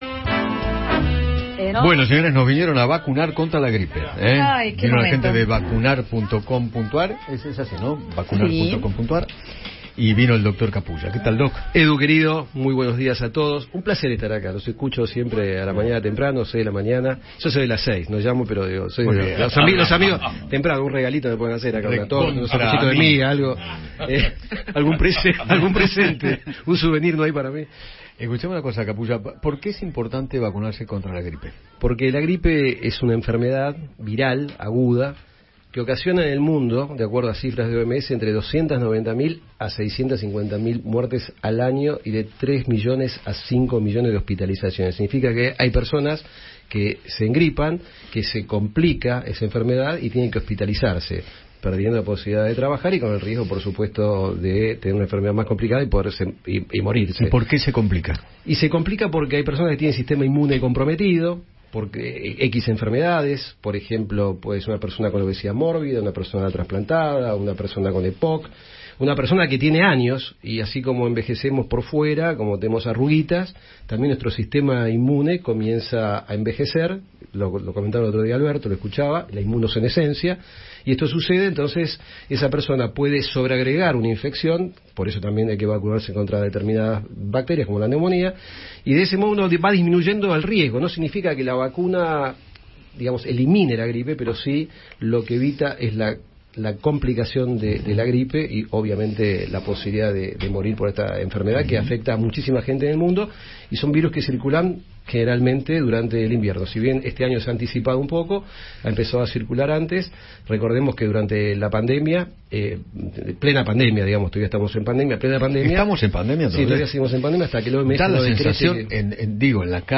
conversó con Eduardo Feinmann sobre la importancia de vacunarse para prevenir ciertas enfermedades.